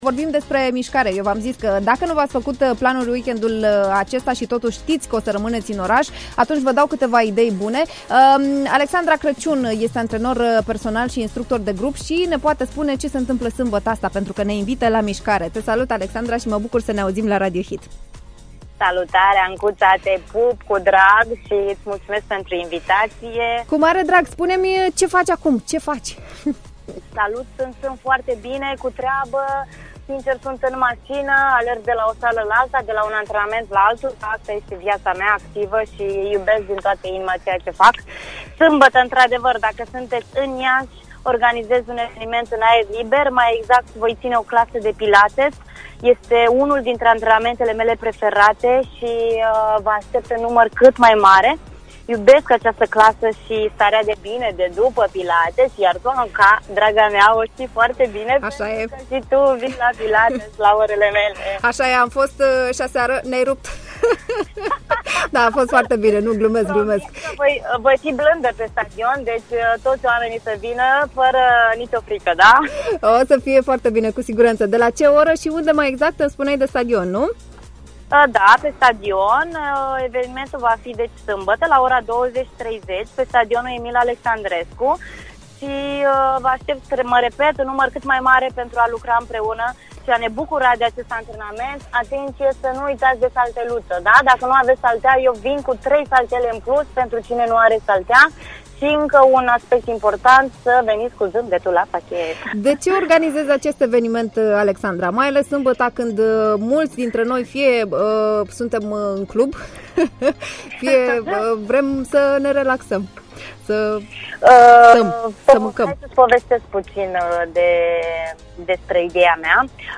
am stat de vorbă